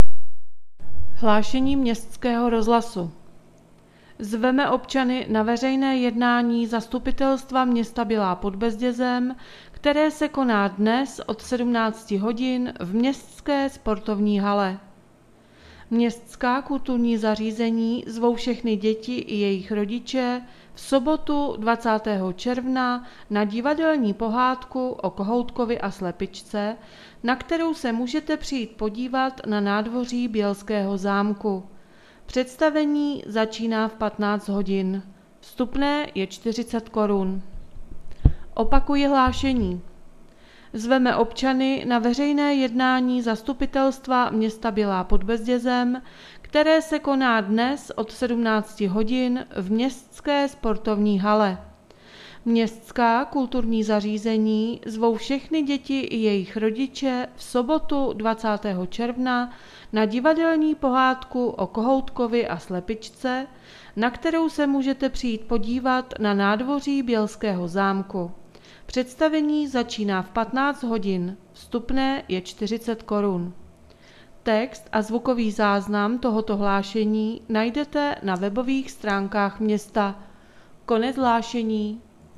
Hlášení městského rozhlasu 17.6.2020